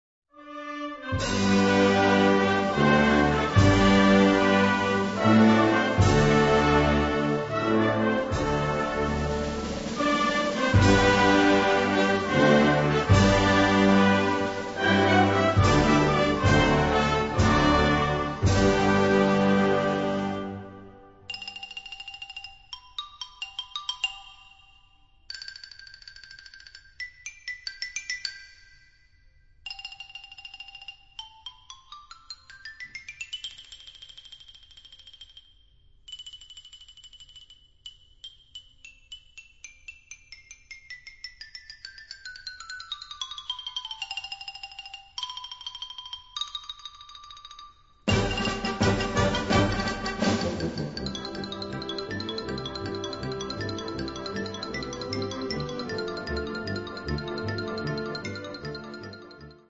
A4 Besetzung: Blasorchester Zu hören auf